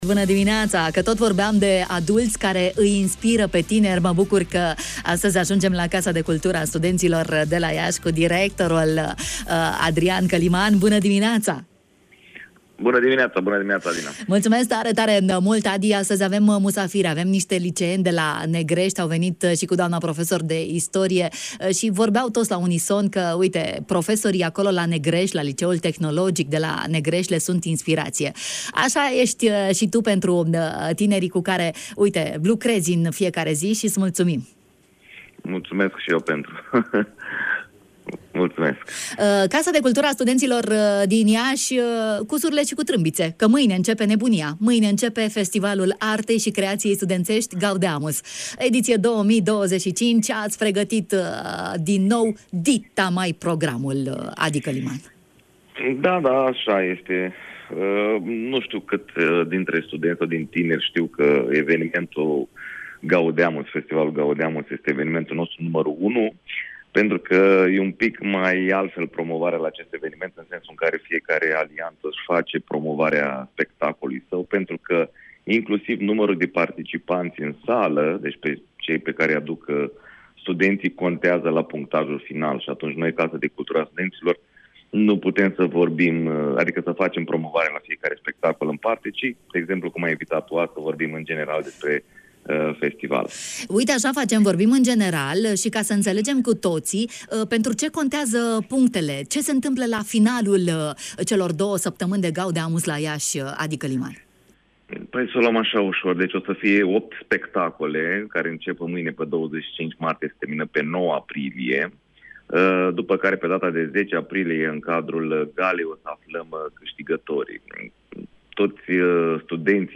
a fost în direct în matinalul de la Radio Iași